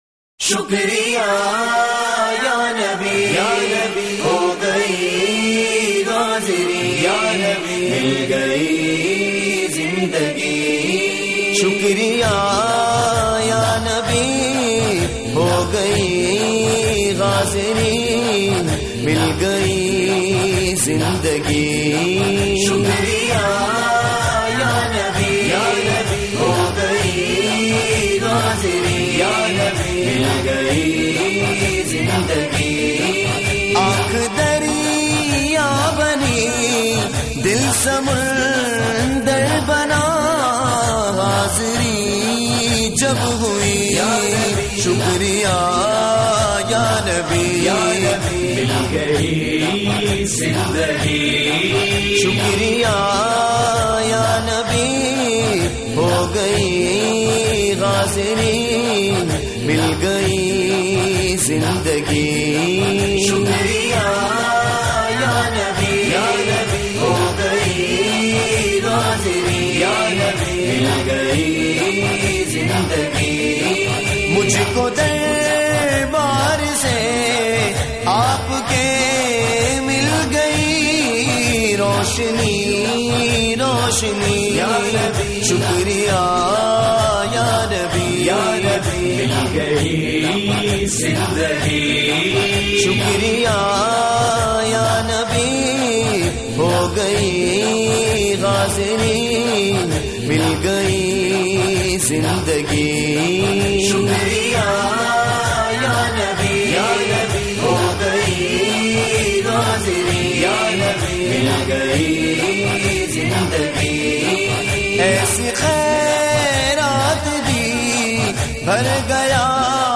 " Naat MP3